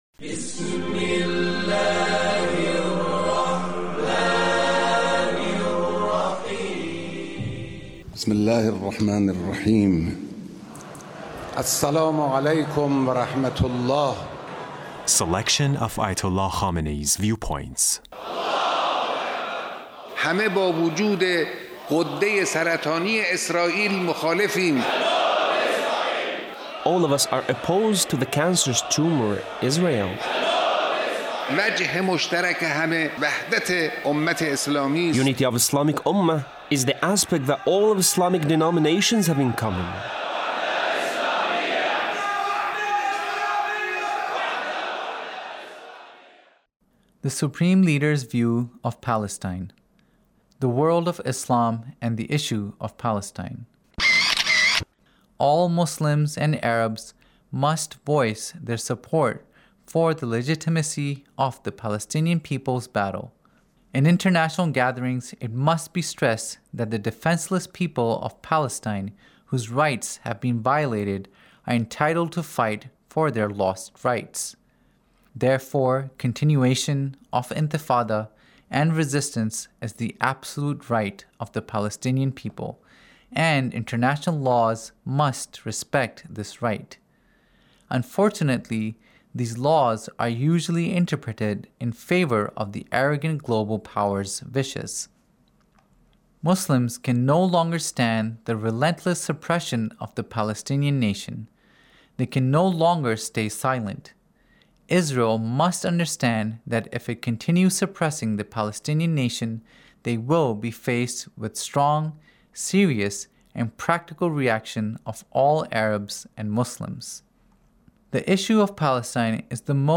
Leader's Speech on Palestine